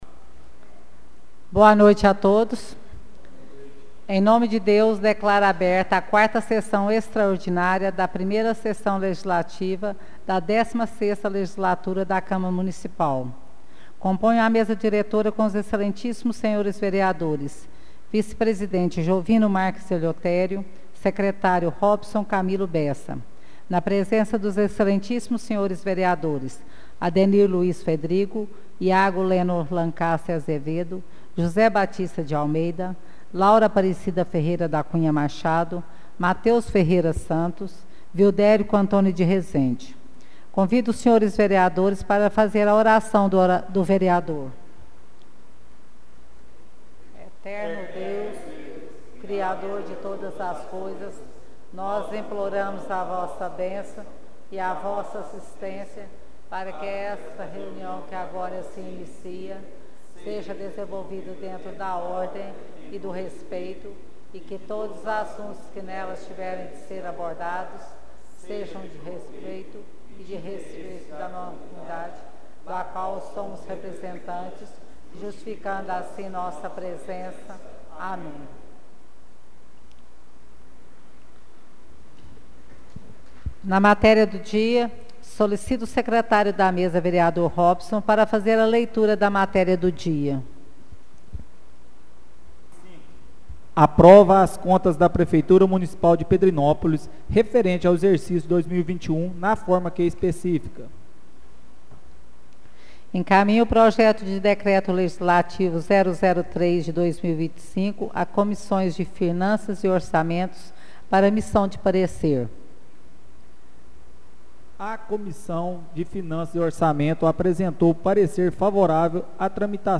Áudio da 4ª Sessão Extraordinária de 2025